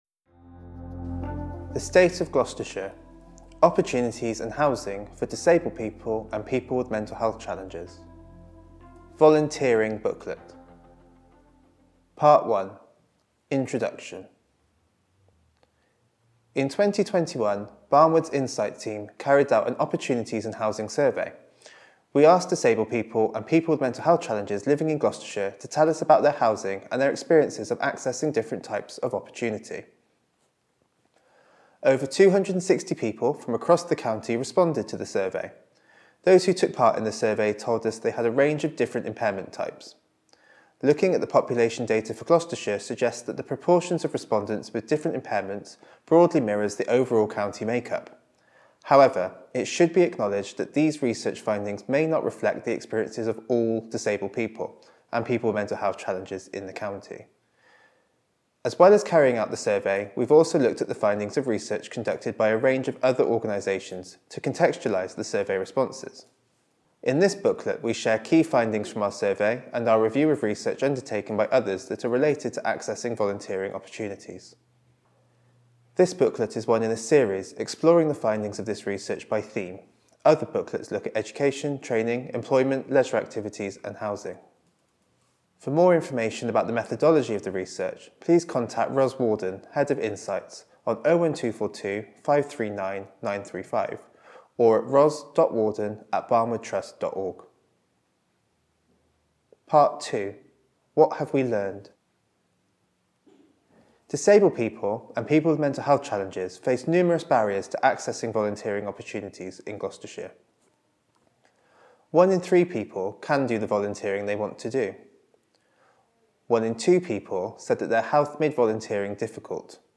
British Sign Language (BSL) translation Subtitled video of the report being read by one of our Researchers Audio recording of the report being read by one of our Researchers Easy Read version to read or download Large print version to read or download